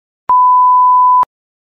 Download Free Beep Sound Effects
Beep